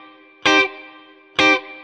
DD_StratChop_130-Cmin.wav